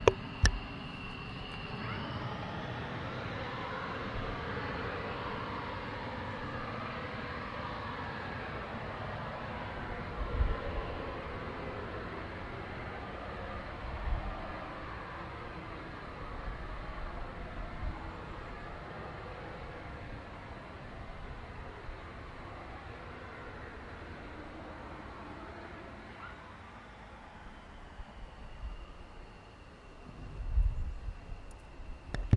比利时F16战斗机引擎启动
描述：比利时F16战斗机引擎启动
标签： 飞机 喷射 引擎 F16 启动 战斗机 空挡
声道立体声